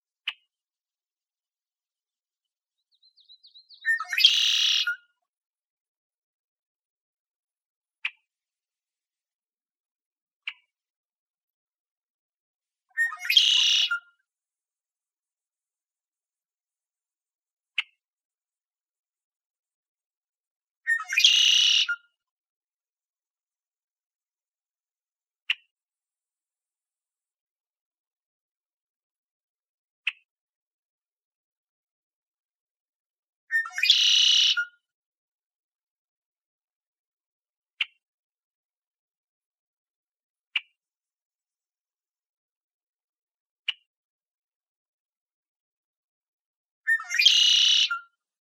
redwingedblackbird.wav